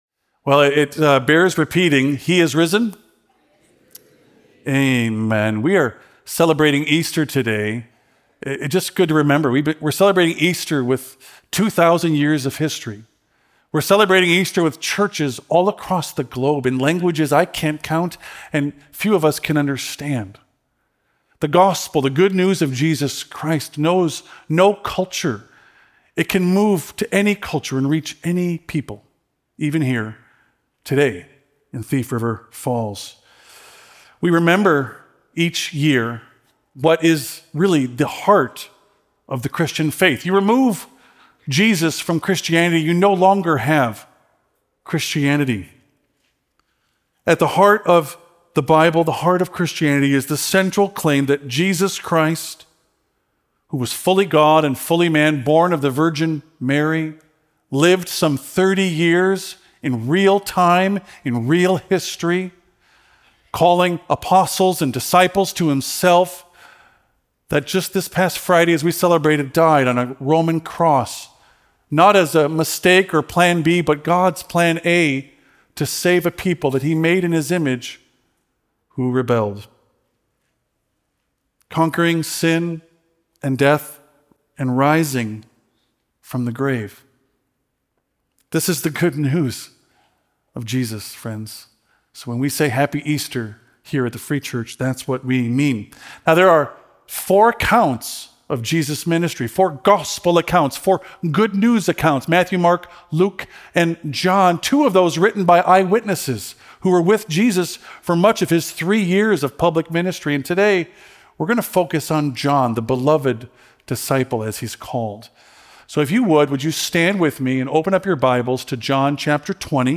Christmas Eve sermon